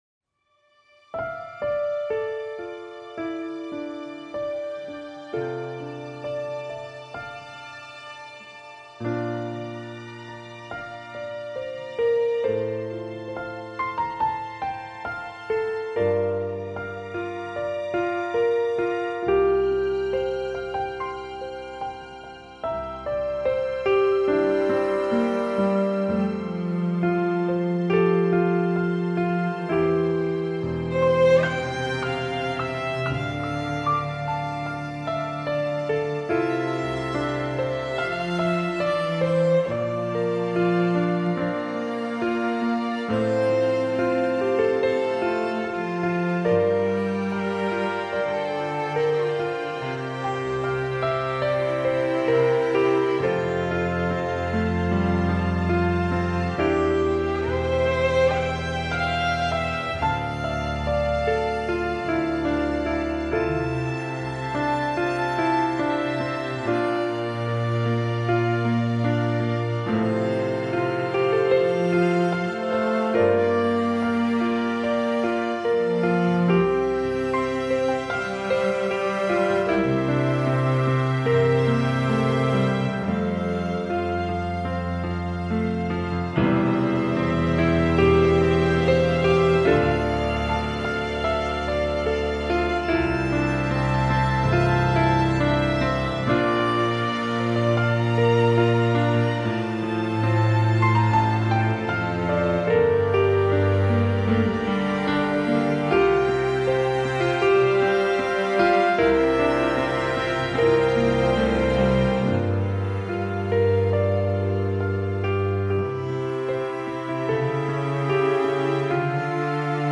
NoLeadVocal   Philippians 2:5-11 Orchestral Humility, Servant Leadership, and Exaltation of Jesus Christ Piano, Strings, Horns